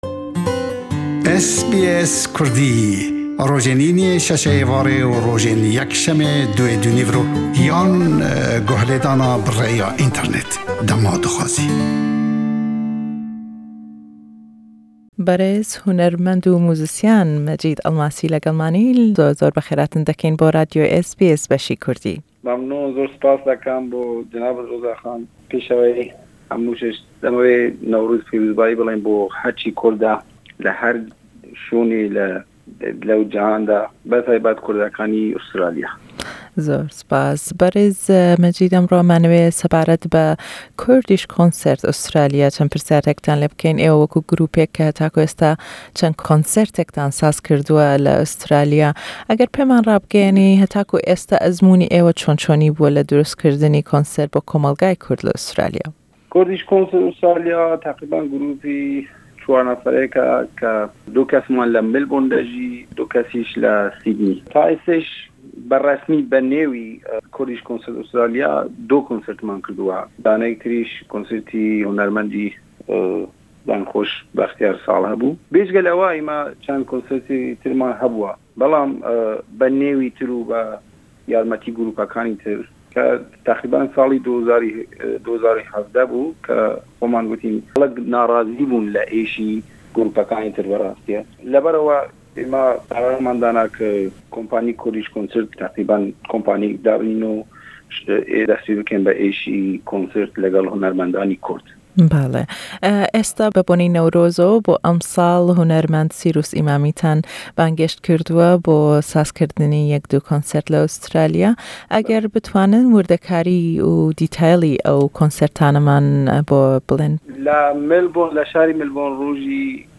Le em lêdwane le gell hunermend û jenyarî Kurd